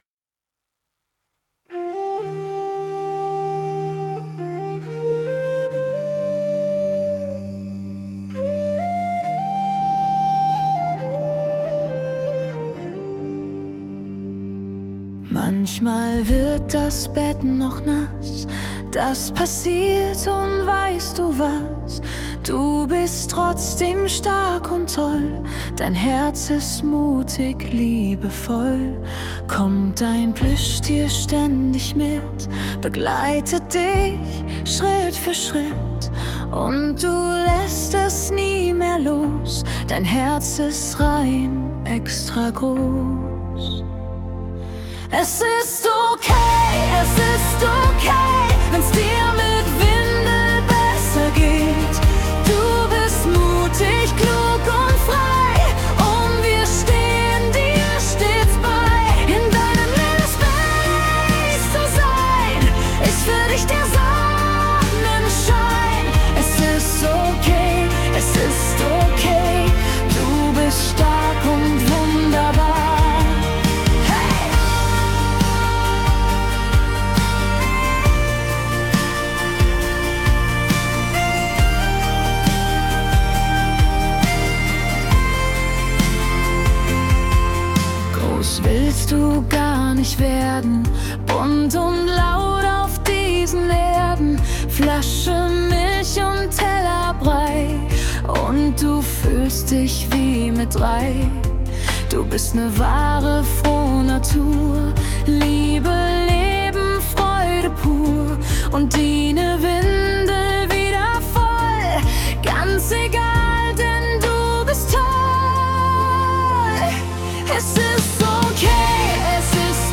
Mit einem federleichten Ton